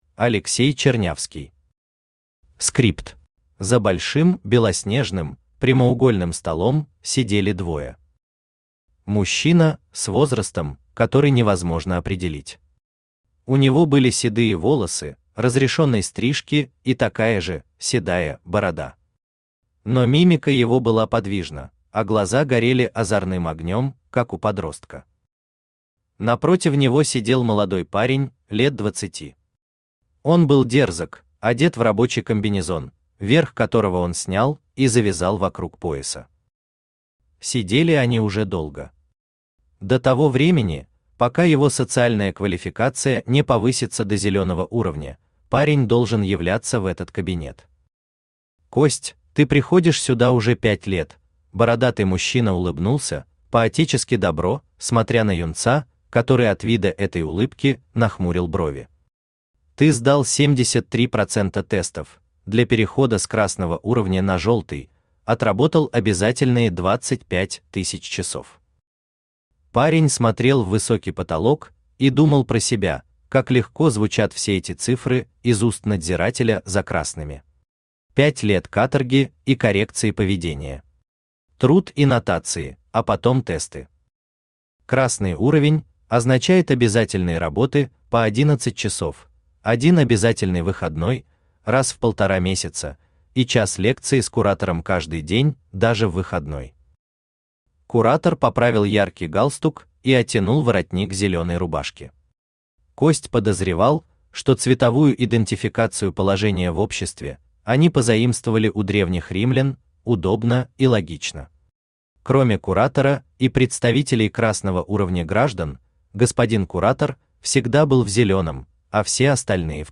Аудиокнига Скрипт | Библиотека аудиокниг
Aудиокнига Скрипт Автор Алексей Андреевич Чернявский Читает аудиокнигу Авточтец ЛитРес.